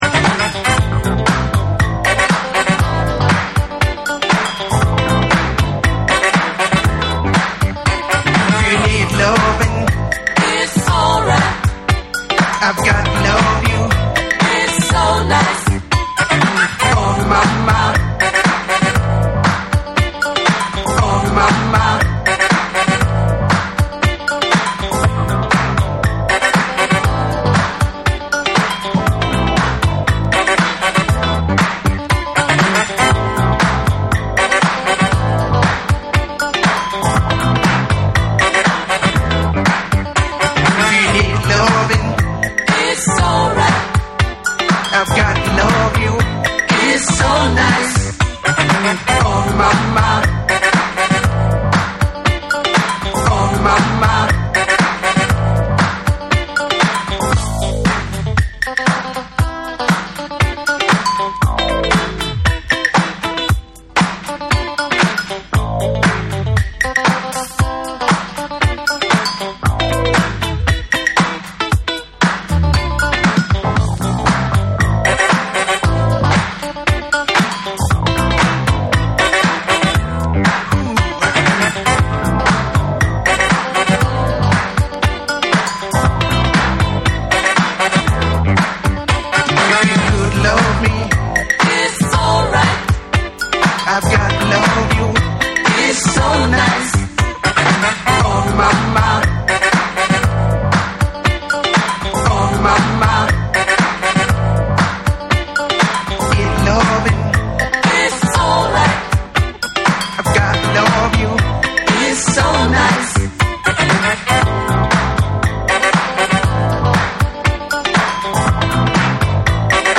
エレクトリックなディスコ・トラックに男性ヴォーカルと女性コーラスが絡み合う3(SAMPLE 3)。
WORLD / DANCE CLASSICS / DISCO